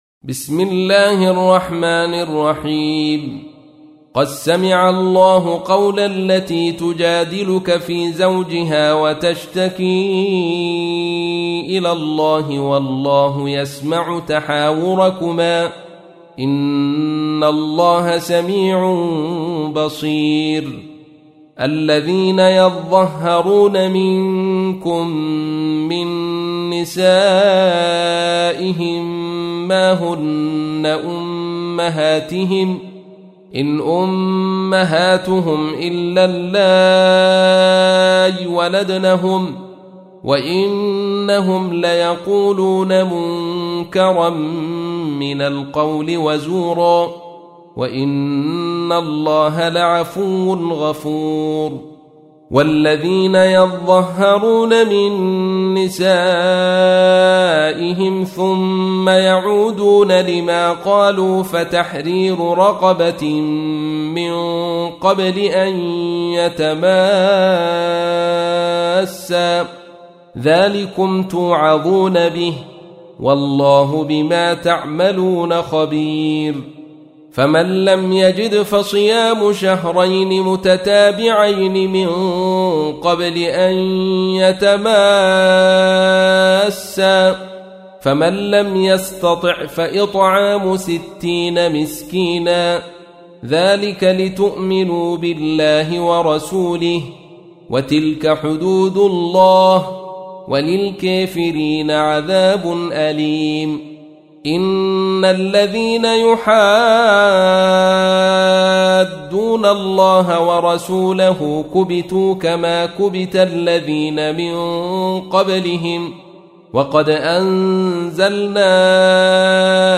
تحميل : 58. سورة المجادلة / القارئ عبد الرشيد صوفي / القرآن الكريم / موقع يا حسين